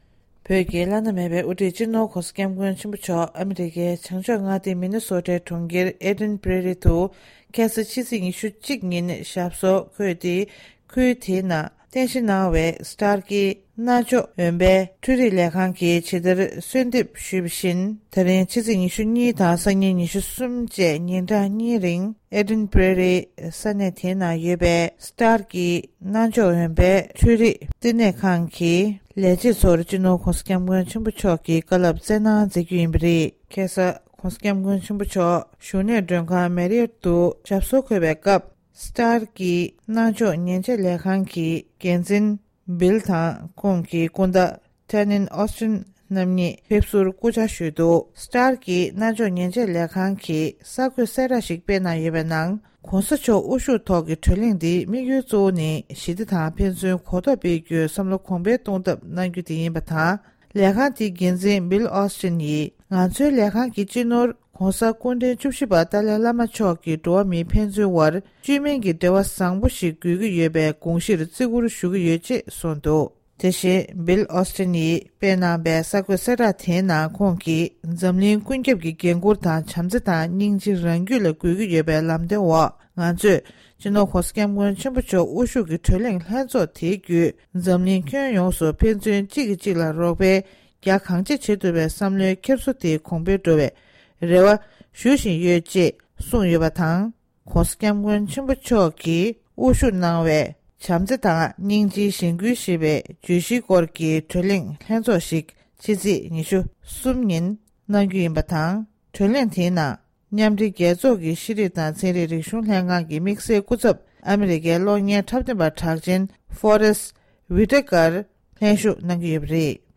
ས་གནས་ནས་བཏང་བའི་གནས་ཚུལ།
སྒྲ་ལྡན་གསར་འགྱུར། སྒྲ་ཕབ་ལེན།